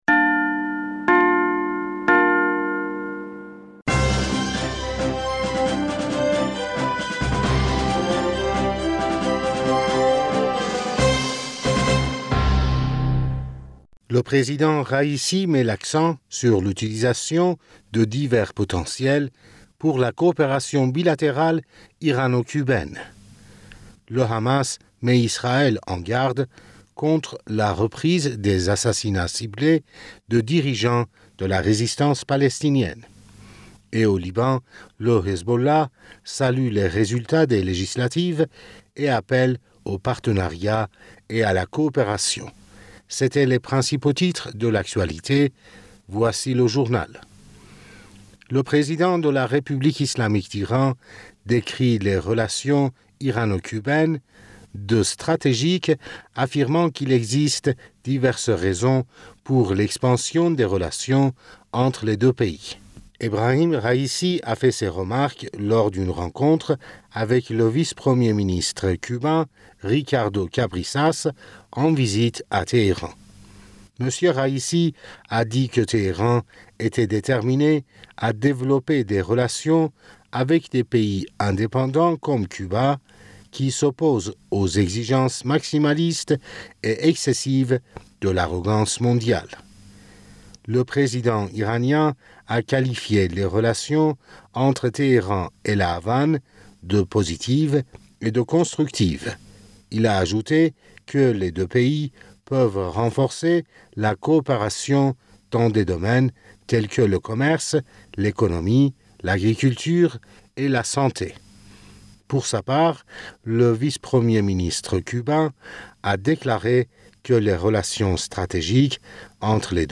Bulletin d'information Du 19 Mai 2022